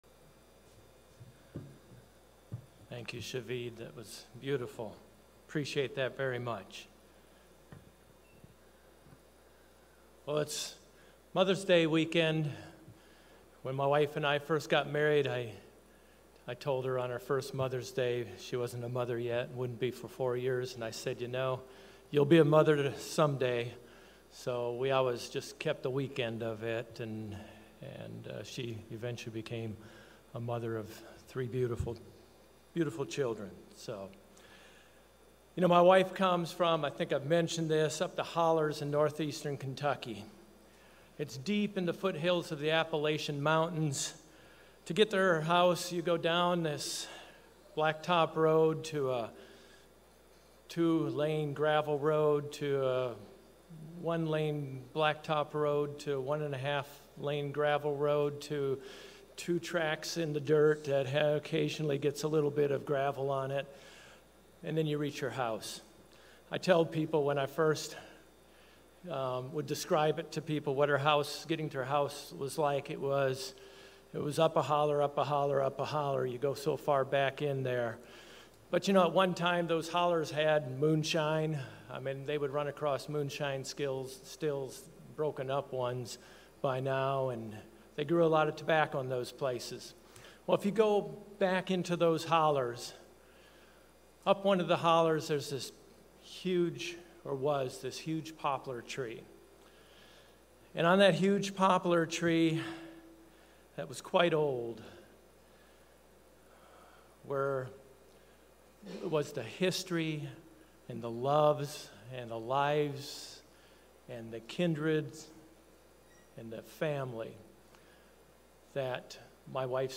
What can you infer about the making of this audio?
Given in Orlando, FL Jacksonville, FL